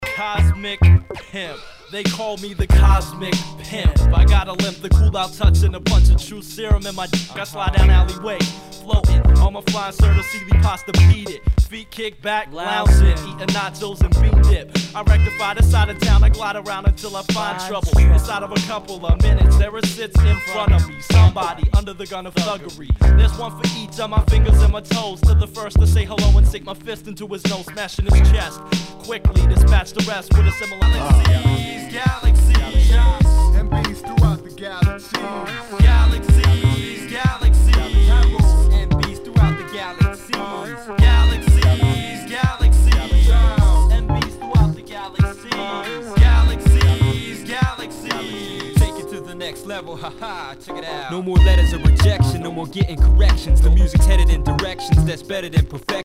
HIPHOP/R&B
全体にチリノイズが入ります。